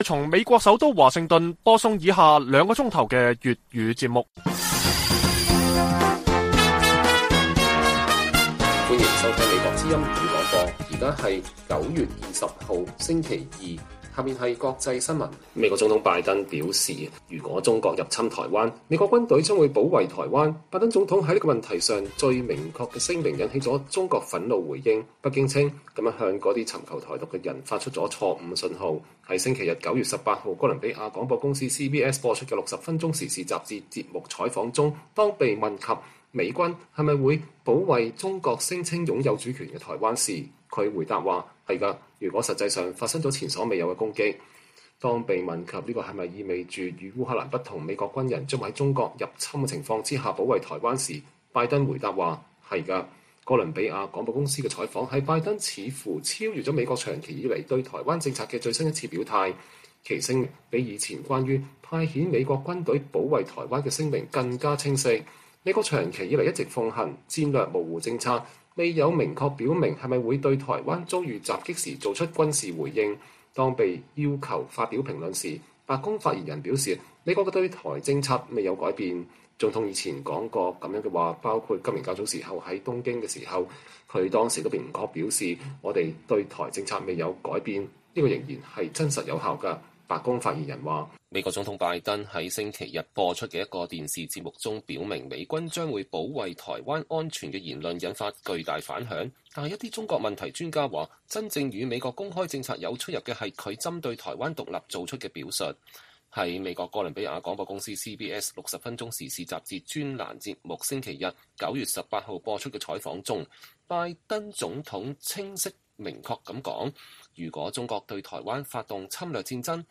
粵語新聞 晚上9-10點: 拜登再提美軍保衛台灣 引發北京表示“強烈不滿”